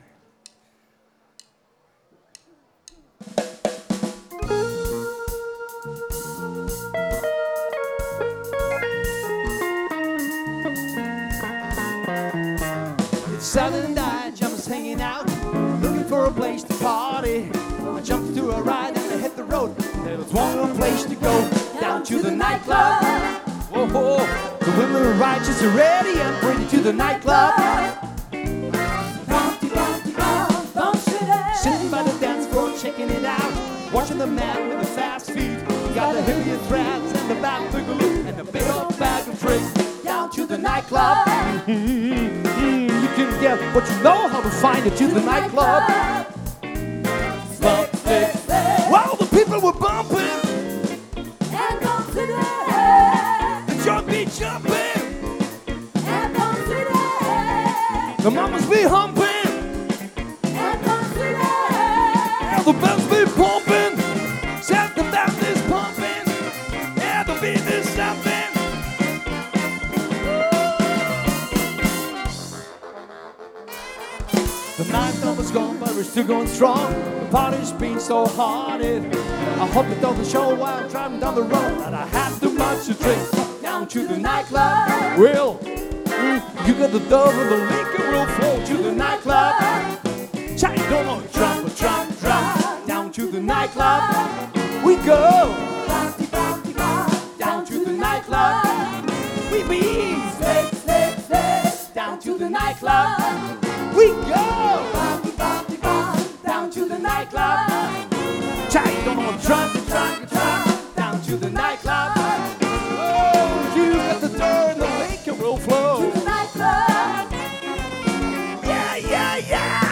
· Genre (Stil): Soul